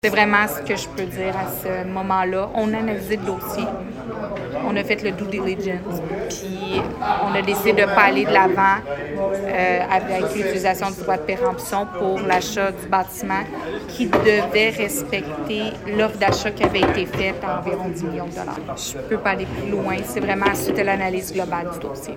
Nous avons tenté de savoir pourquoi auprès de la mairesse, Julie Bourdon, voici sa réponse :